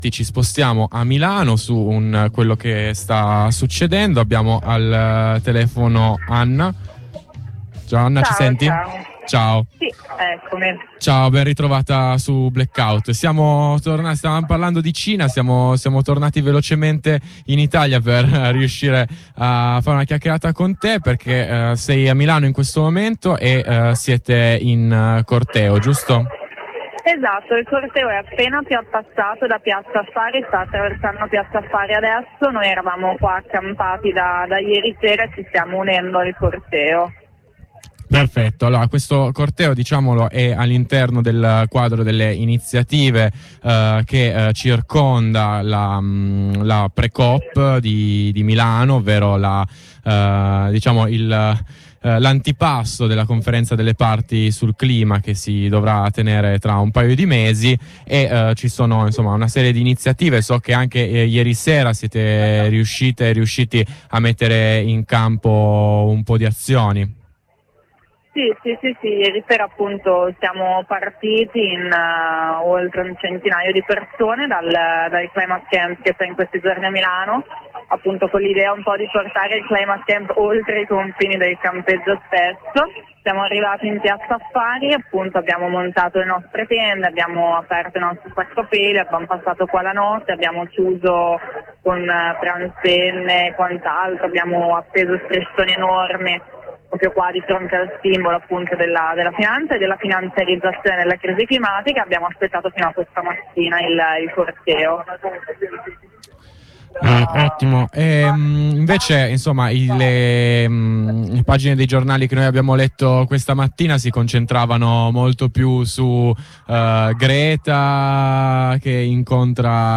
Per domani pomeriggio, alle 15 è atteso un grande corteo per il centro di Milano, raccontiamo questi momenti in diretta: